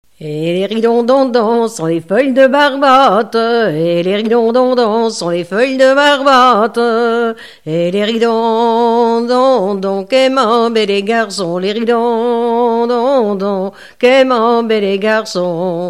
grand'danse
danse : ronde : grand'danse
Pièce musicale inédite